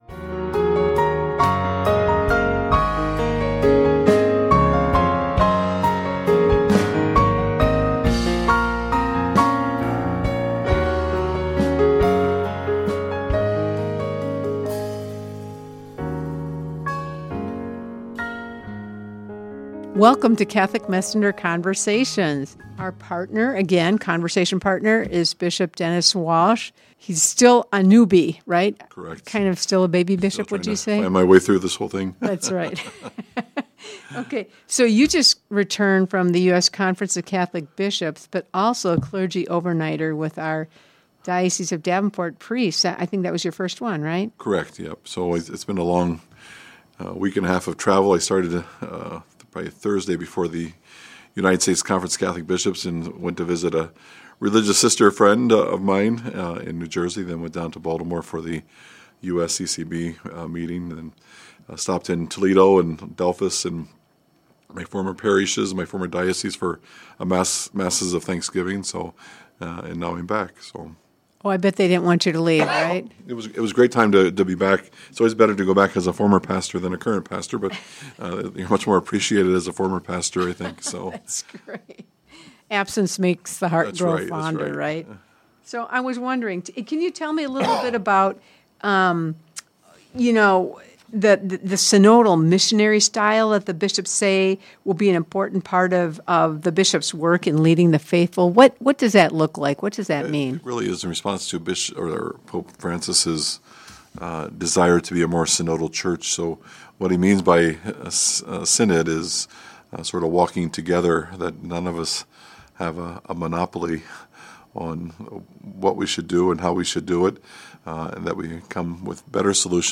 Bishop Walsh shares his thoughts from his first meeting as a member of the U.S. Conference of Catholic Bishops.
This segment was produced and recorded at KALA Radio Studios, St. Ambrose University, Davenport, Iowa, USA.